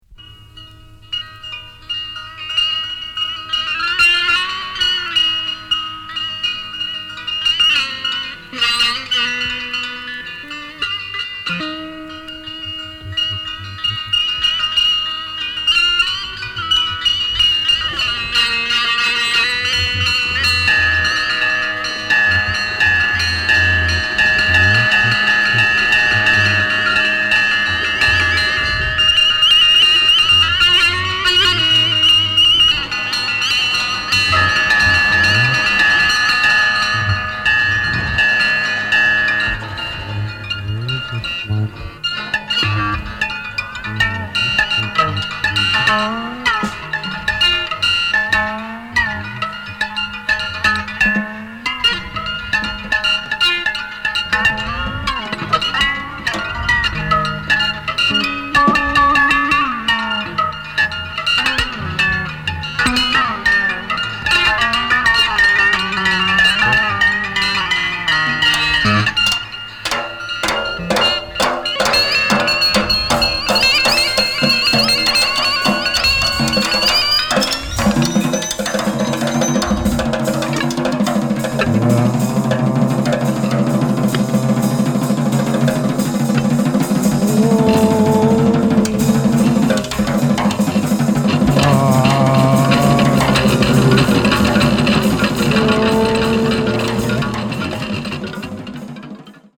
即興
ギター